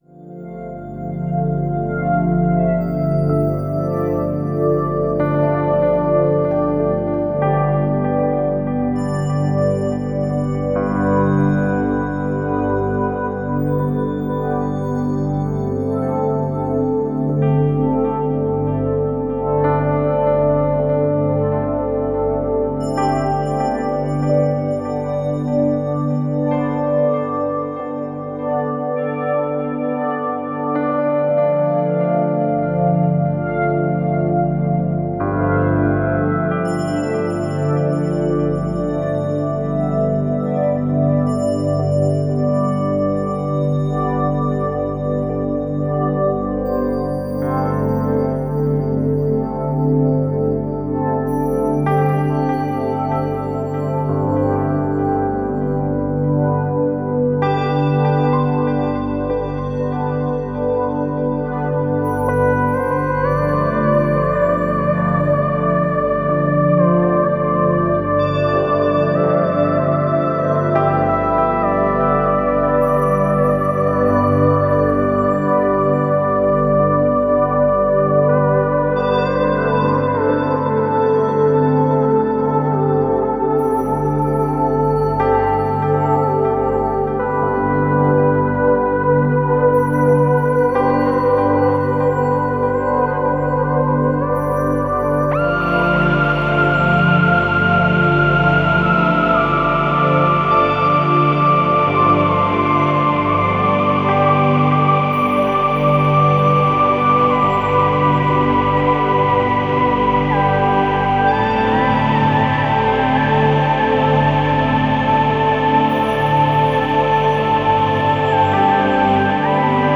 Musik für atmosphärische Abendstunden!
Synthesizersounds zum Relaxen!